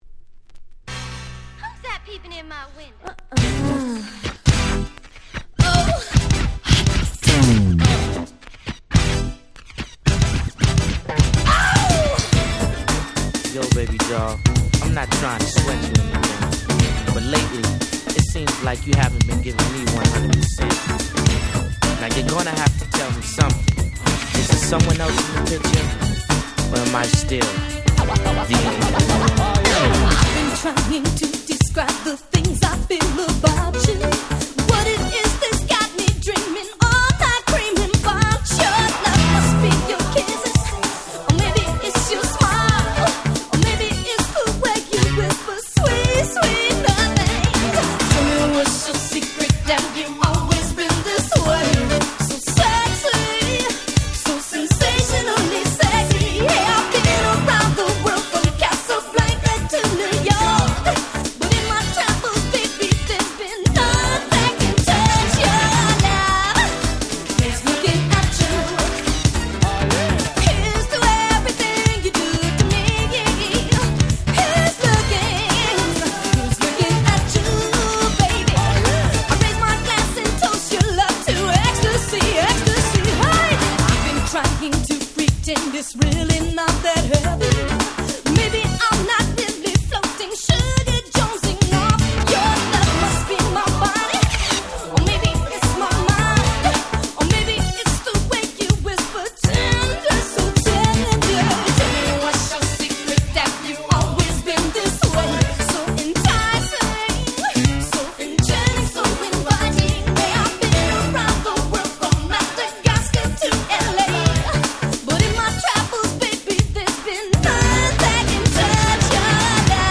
90' Nice New Jack Swing !!
ハネたBeatにエモーショナルな彼女のVocalが堪らない最高のNew Jack Swing !!
NJS ニュージャックスウィング ハネ系